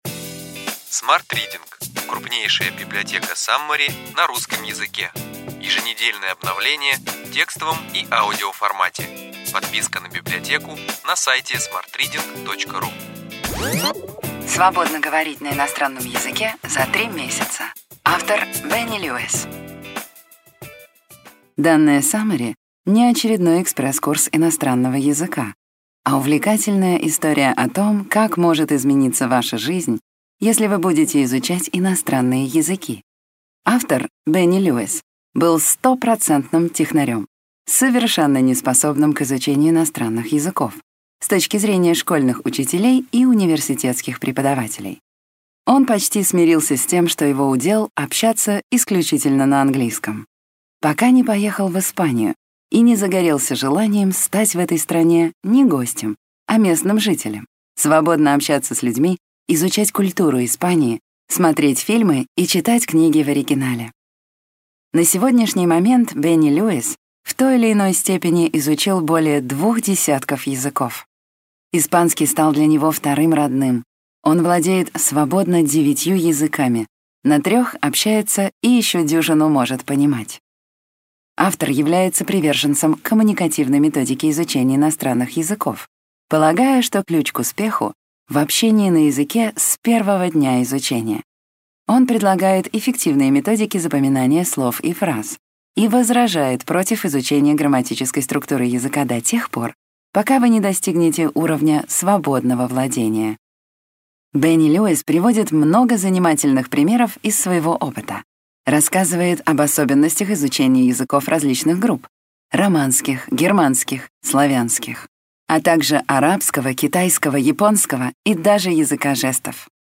Аудиокнига Ключевые идеи книги: Свободно говорить на иностранном языке за 3 месяца.